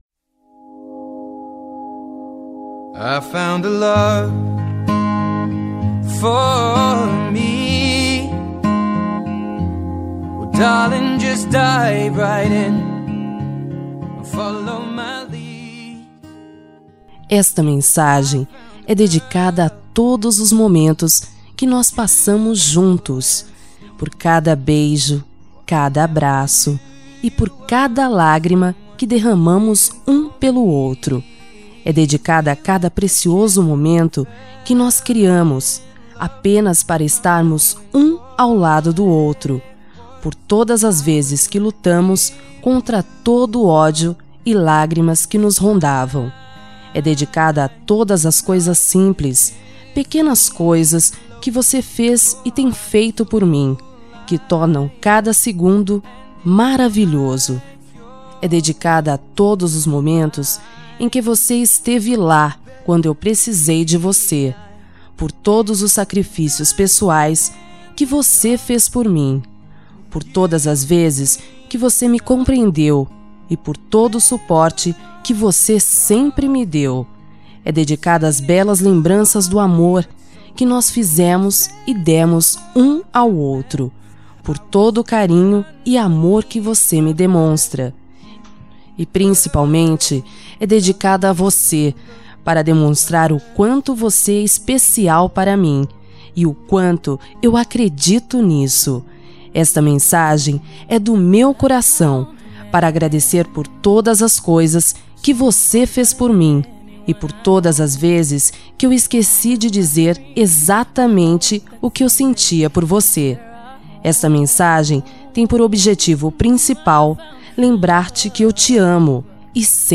Telemensagem de Agradecimento – Romântico – Voz Feminina – Cód: 571